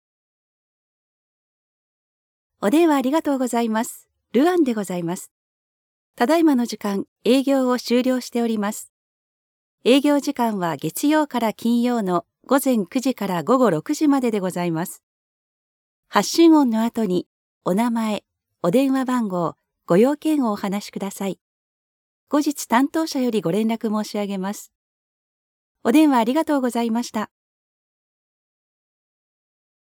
Natuurlijk, Veelzijdig, Vertrouwd, Warm, Zacht
Telefonie